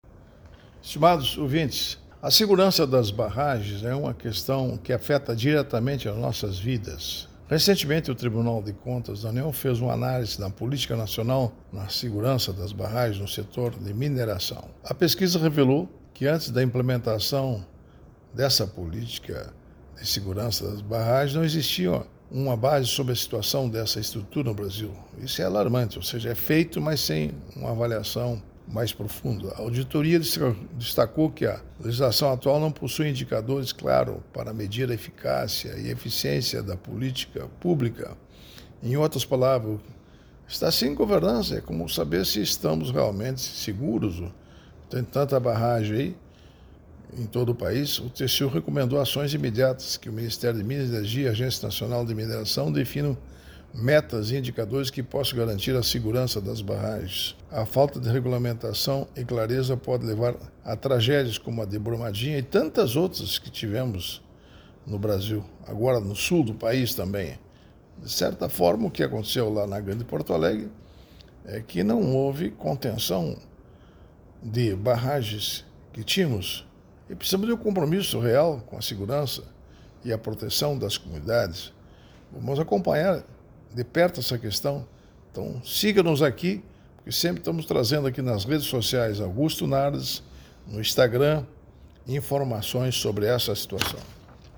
Comentário de Augusto Nardes, ministro do TCU.